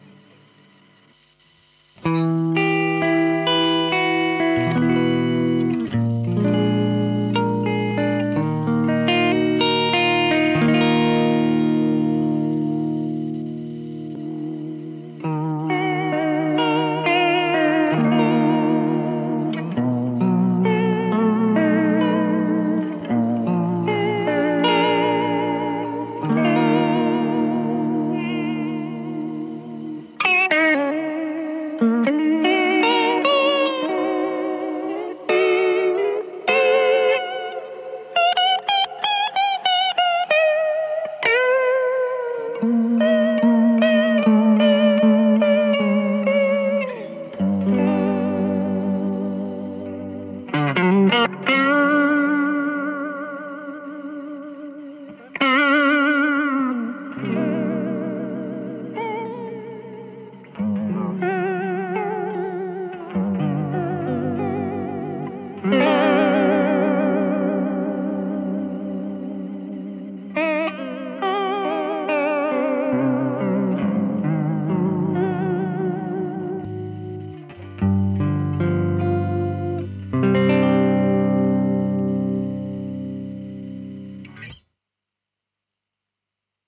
Ακου περίπου πως είναι το mako. Στεγνός ήχος από το ampero και μετά ένα από τα τρέμολό του μαζί με διάφορα space και lo-fi εφέ. trem.mp3 Attachments trem.mp3 trem.mp3 1.7 MB